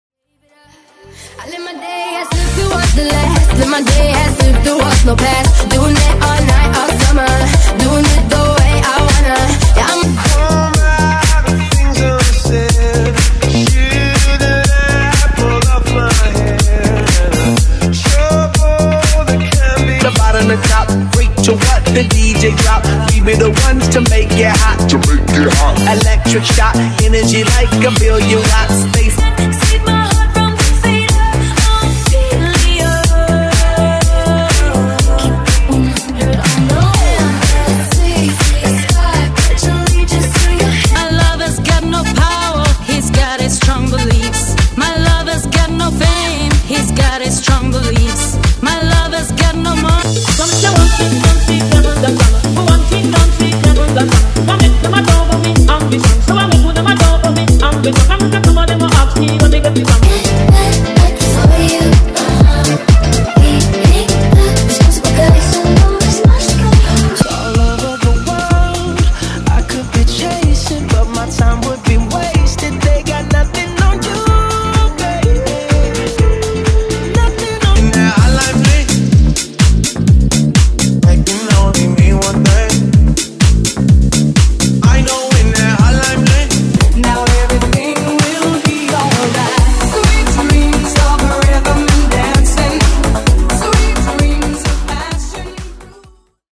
No. 64 in DANCE
Genre: Version: BPM: 115 Time: 33:32